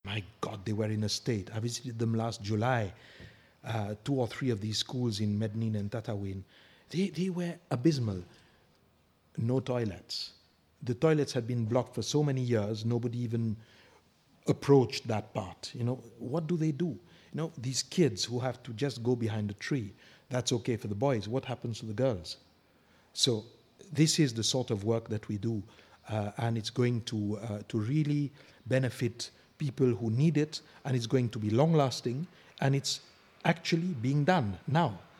Ambassador Pierre Combernous describes what he found on a school visit.